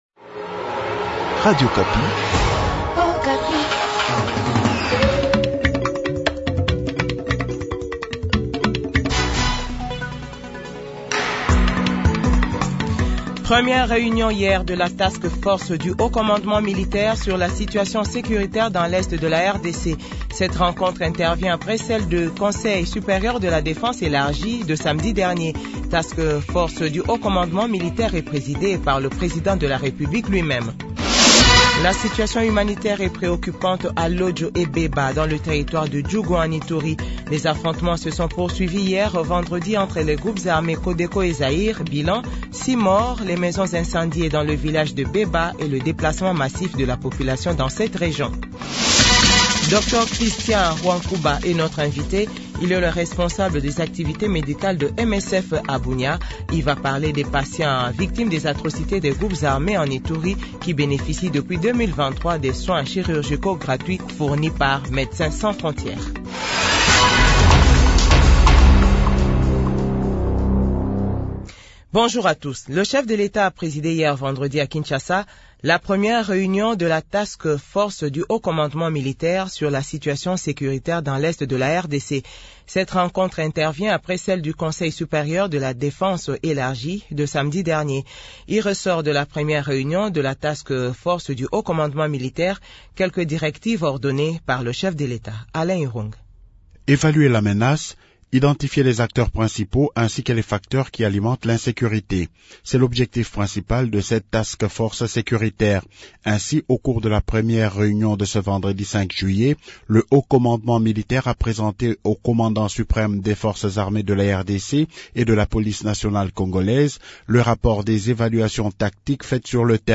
JOURNAL FRANCAIS 12H00